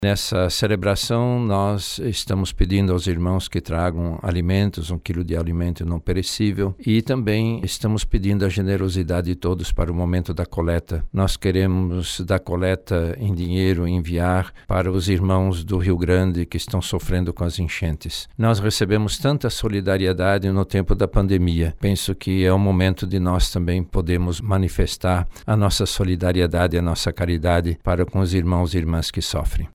O Arcebispo Metropolitano de Manaus e Cardeal da Amazônia, Dom Leonardo Steiner, destaca a importância da doação de alimentos e da ajuda financeira aos irmãos do Rio Grande do Sul, afetados pelas enchentes.